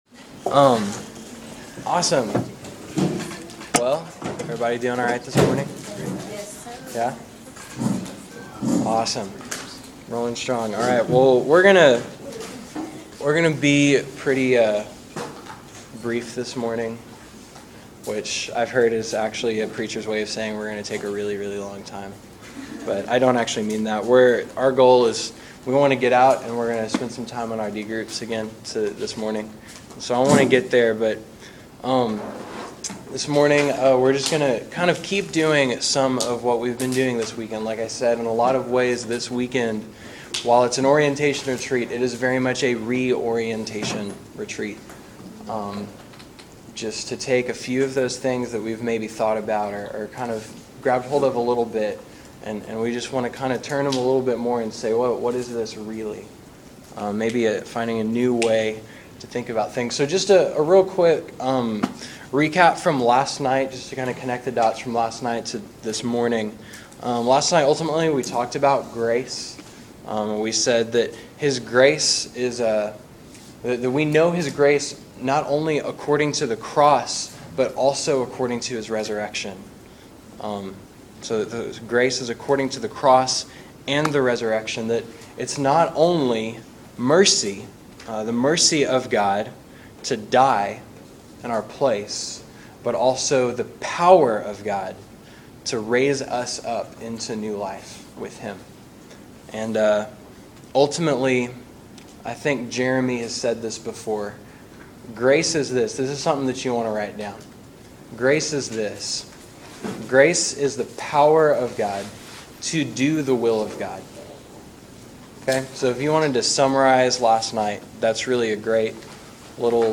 This teaching is from our opening [re]orientation retreat.